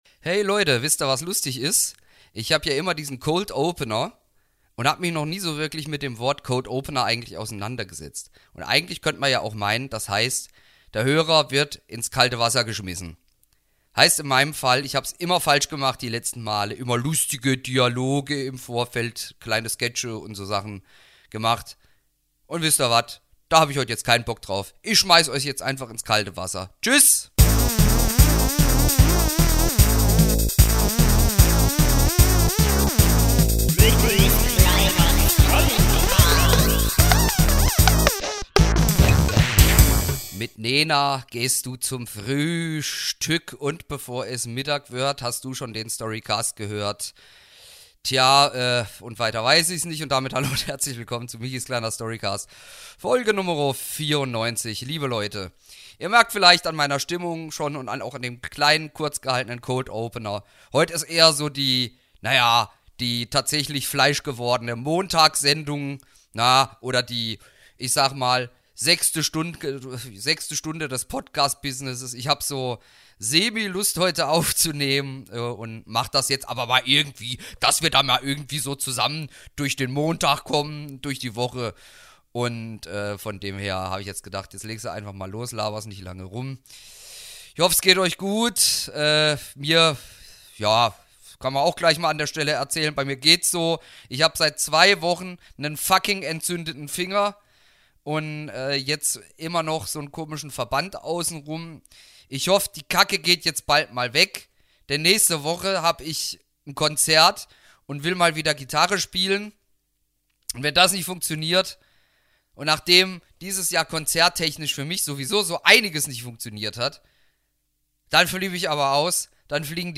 In dieser Folge widmet er sich wieder seinem Frühwerk "Herribert Krumm" und trägt in bekannter Manier Szene 7 bis 9 aus dem Drama vor.